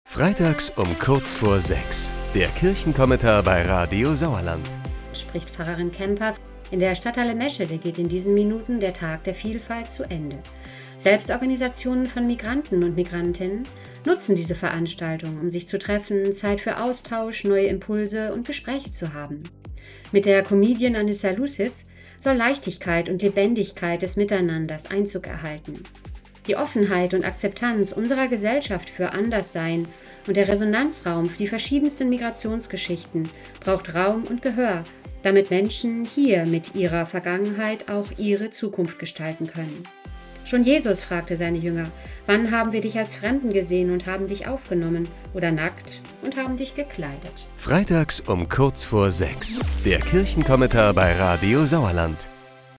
Kirchenvertreter greifen aktuelle Themen auf, die uns im Sauerland bewegen.